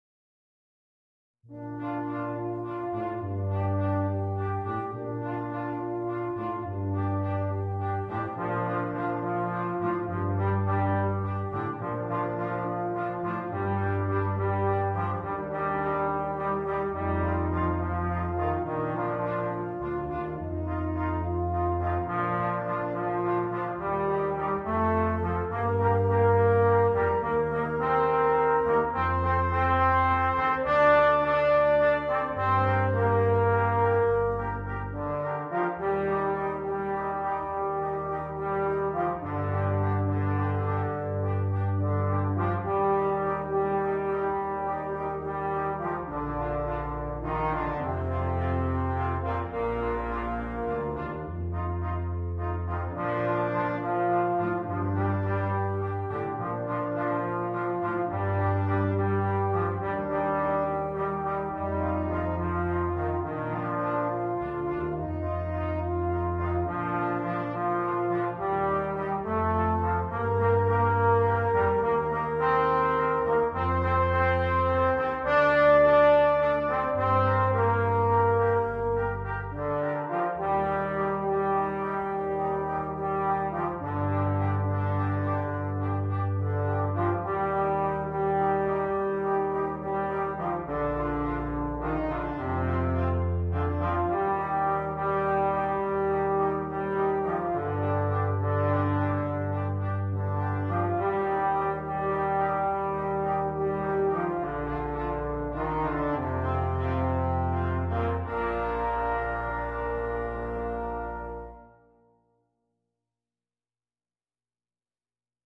брасс-квинтет